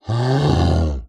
Звук издаваемый гризли